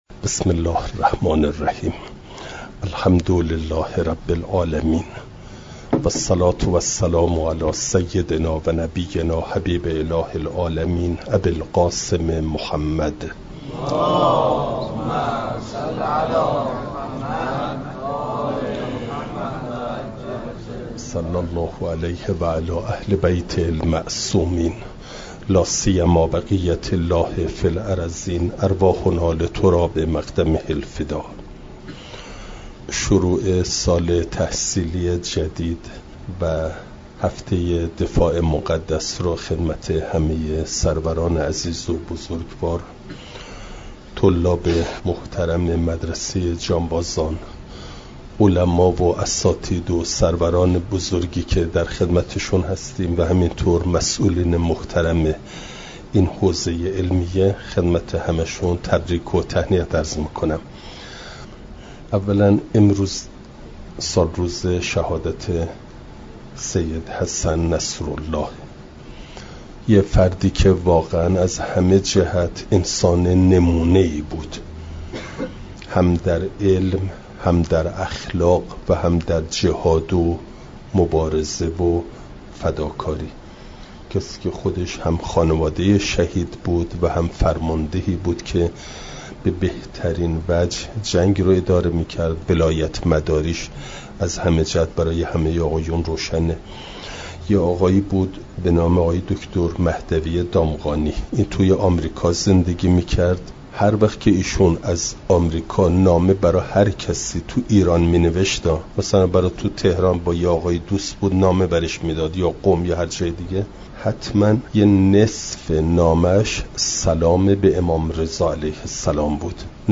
یکشنبه ۶ مهرماه ۱۴۰۴، حوزه علمیه حضرت ابوالفضل علیه السلام(جانبازان)